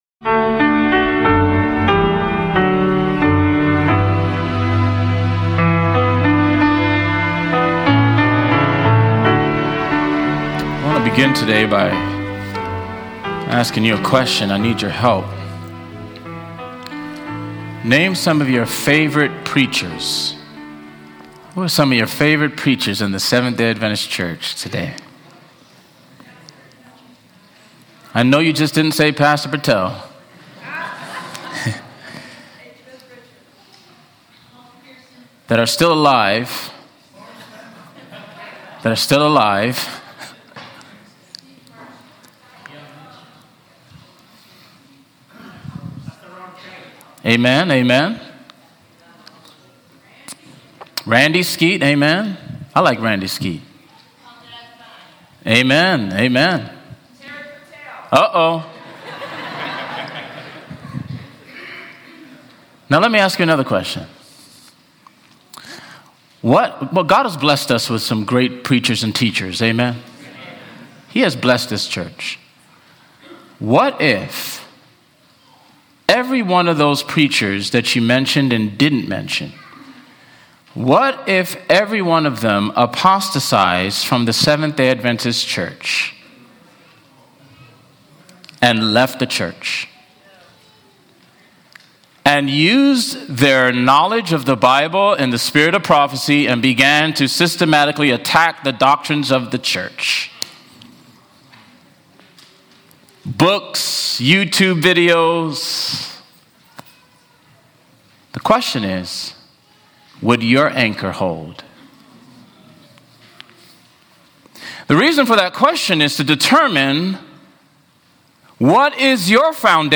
This sermon explores the profound impact of teachers, the necessity of diligent Bible study, and the dangers of false prophecy. Through biblical examples like the twelve spies and the contrast between Jeremiah and Hananiah, the message urges believers to test their faith, discern truth from error, and stand firm in God’s Word amidst life's trials.